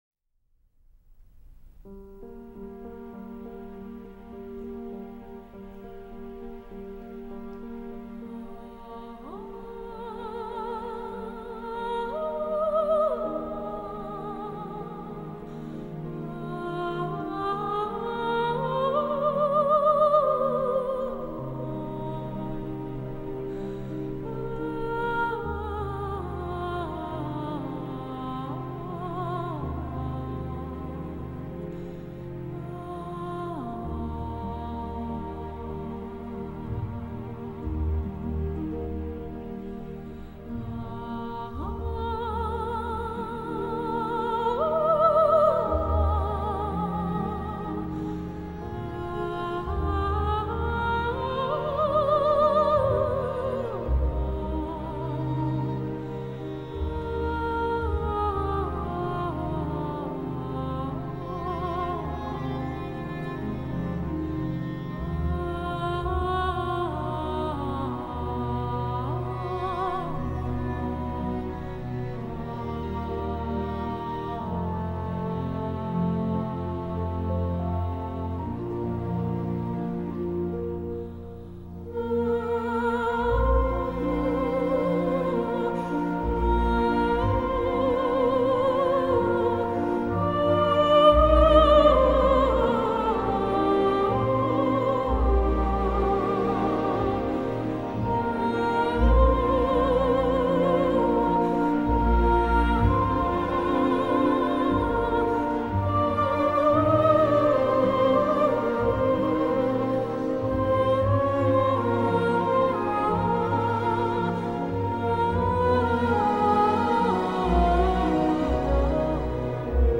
typically grandiose orchestral score